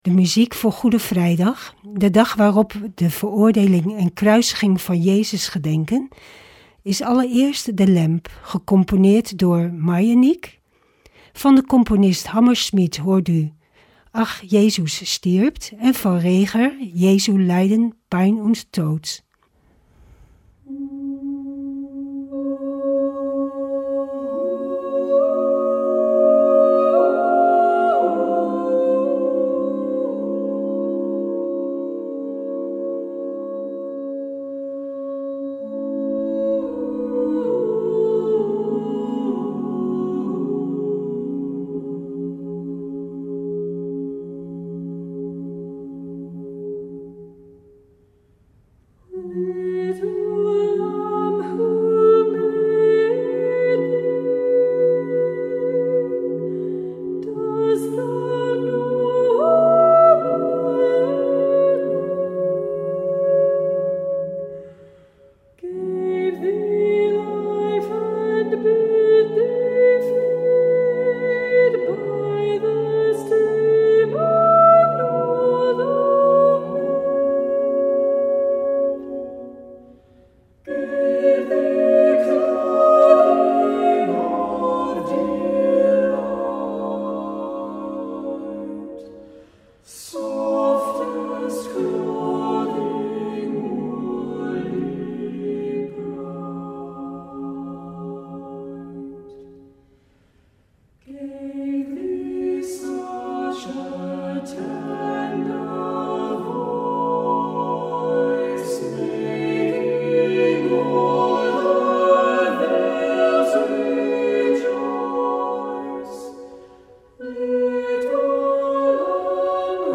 Opening van deze Goede Vrijdag met muziek, rechtstreeks vanuit onze studio.